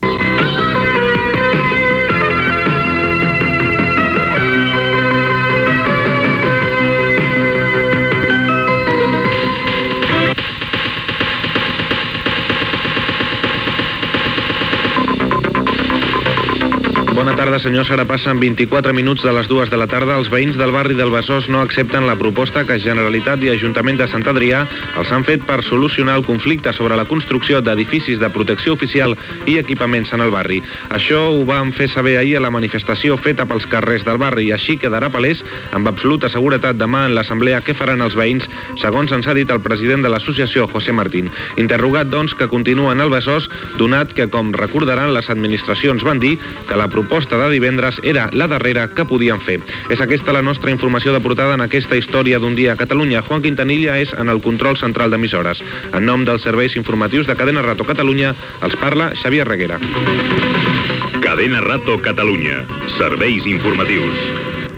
Sintonia de l'emissora, hora, inici de l'informatiu: Barri del Besós.
Informatiu